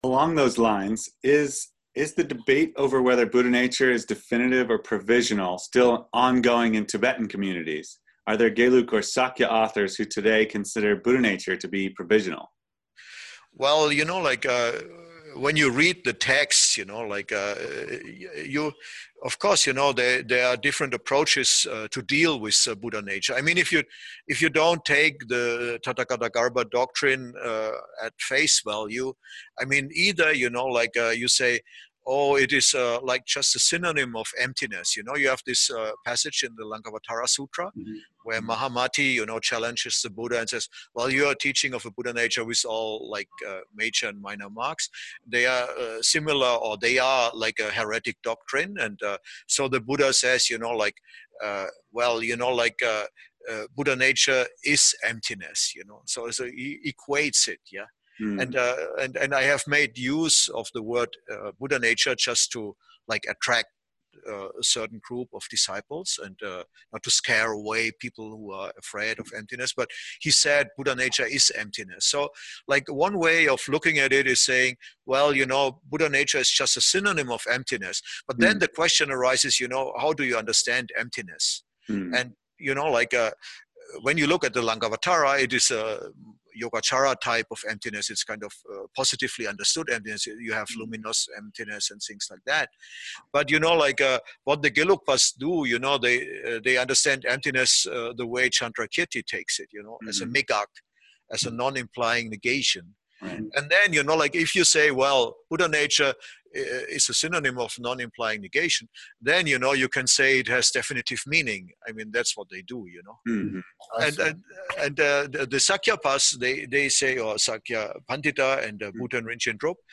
Interview on Buddha-Nature